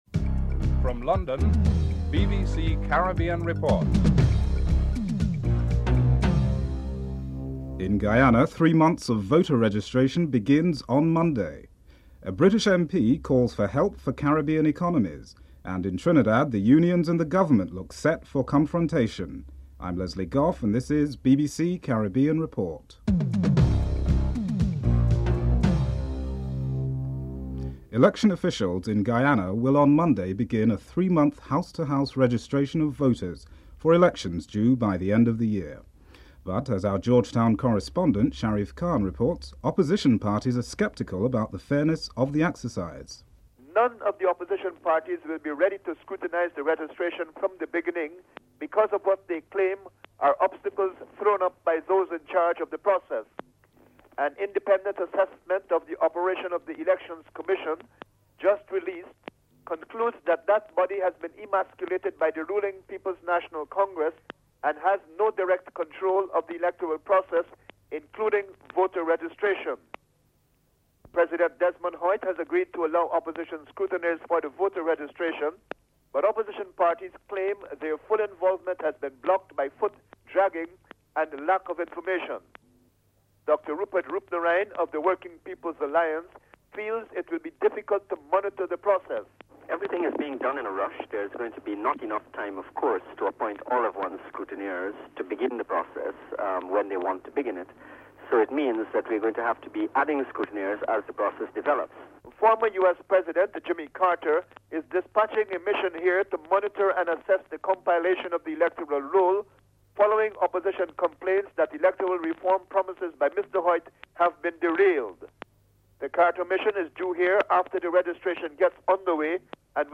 Segment 7: Contains clip of Mr. Selby Wilson addressing the House of Representative on the state of the Trinidad and Tobago economy.
1. Headlines (00:00-00:28)